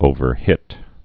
(ōvər-hĭt)